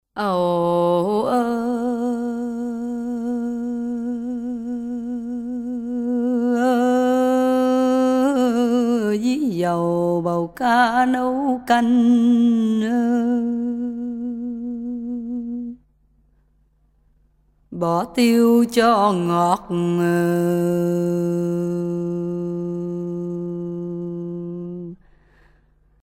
berceuse
Pièce musicale éditée